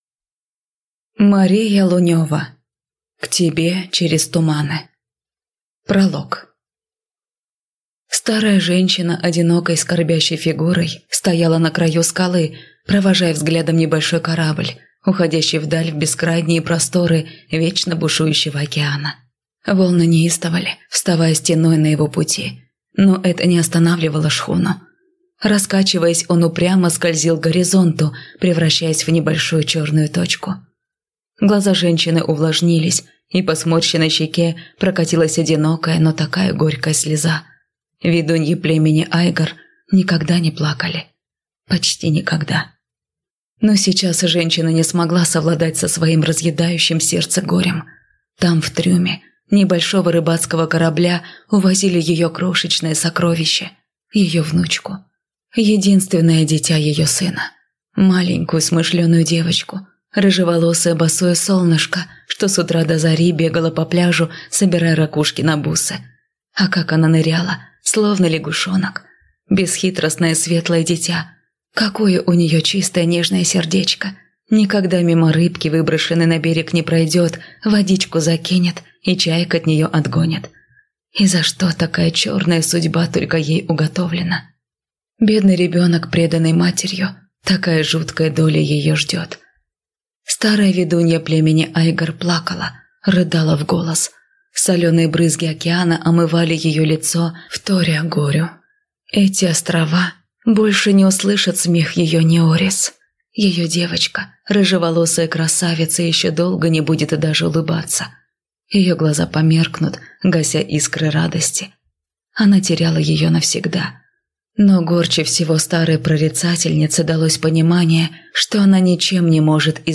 Аудиокнига К тебе через туманы | Библиотека аудиокниг
Прослушать и бесплатно скачать фрагмент аудиокниги